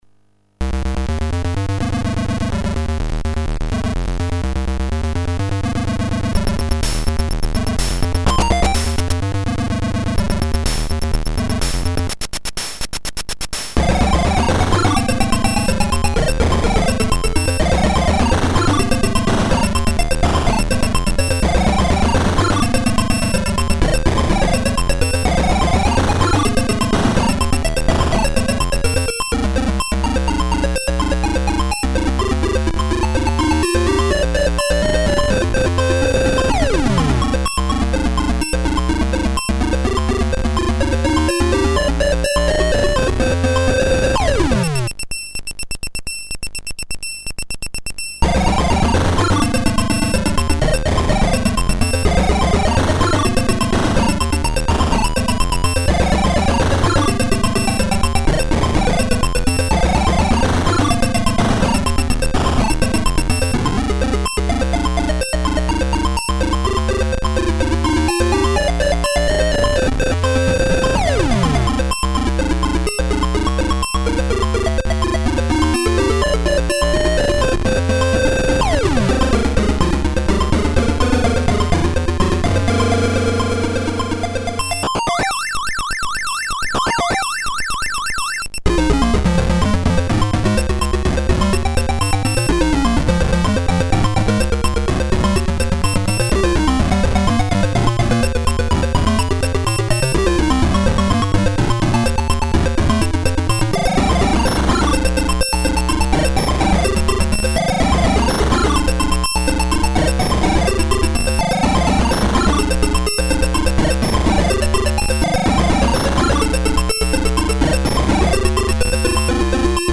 Érdemes összehasonlítani az emulátor hangjával!
Ez sem zajosabb ez emulátorból készült felvételnél.